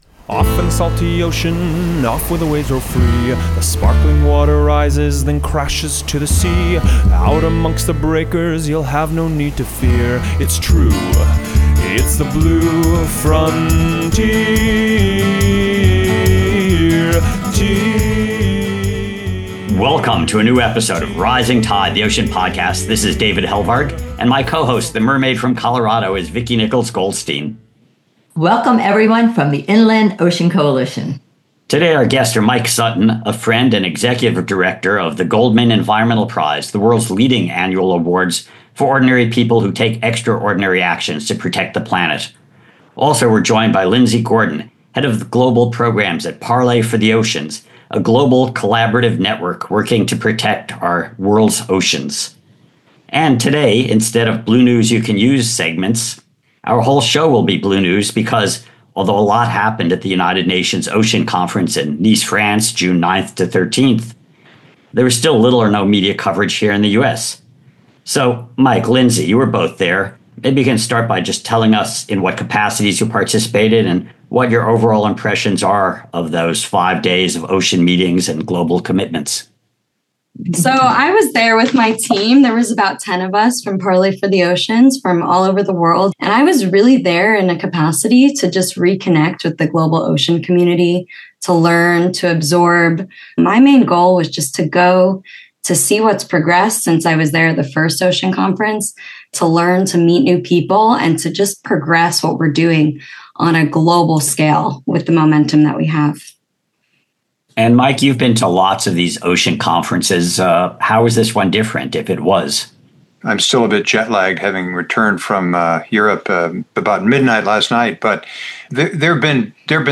So, Join us and dive in for a lively and hopeful world-spanning discussion.